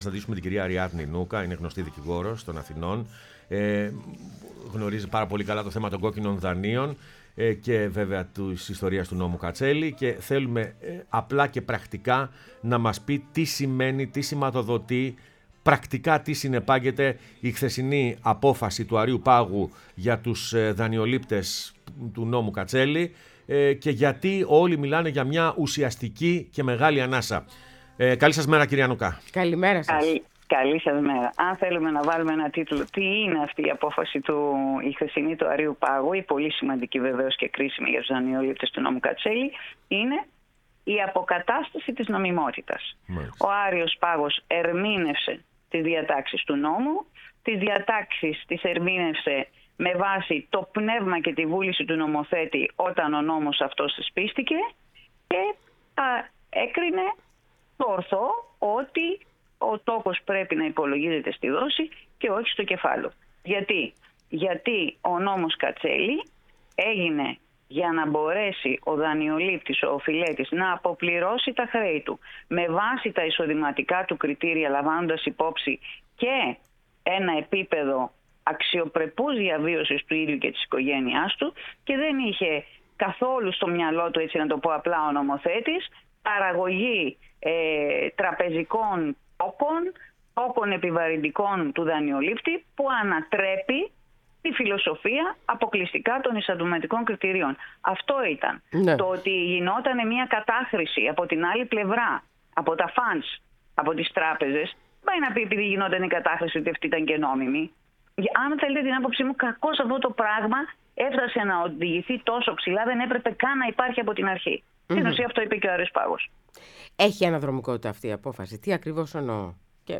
μίλησε στην εκπομπή «Πρωινές Διαδρομές»